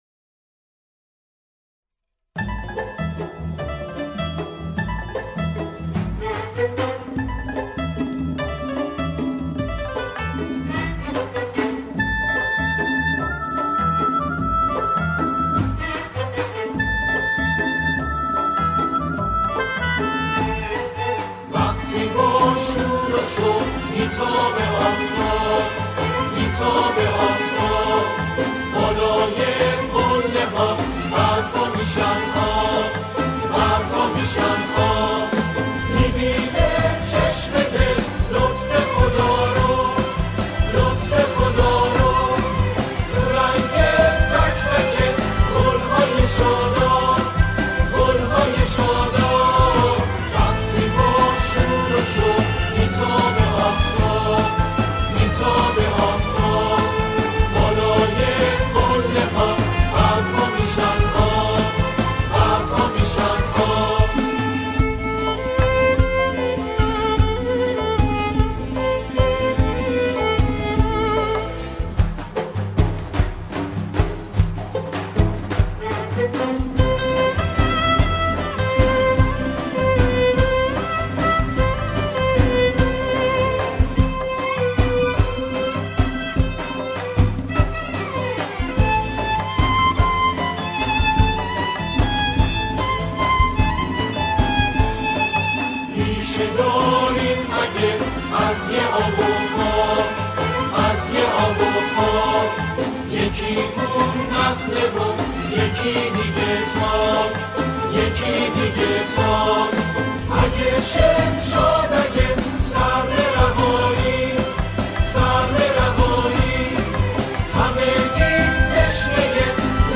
بوسیله گروهی از جمعخوانان اجرا شده است.